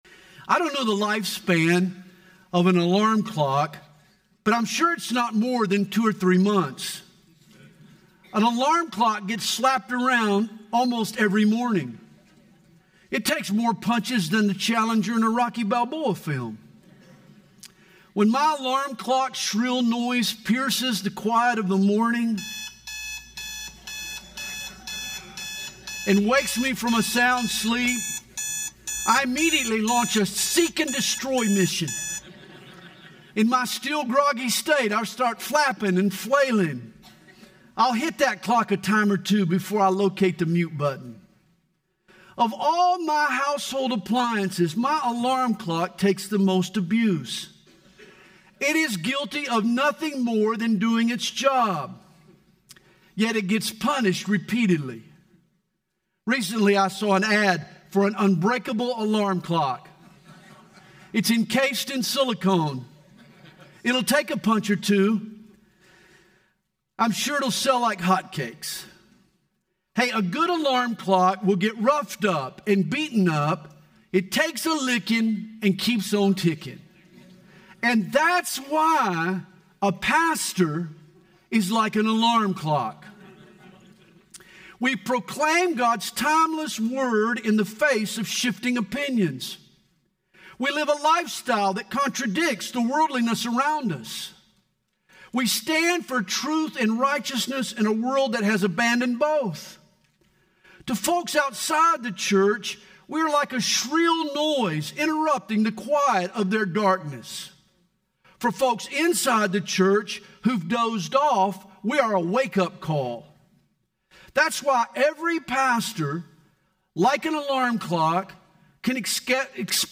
Home » Sermons » “The Wounded Pastor”
Conference: Pastors & Leaders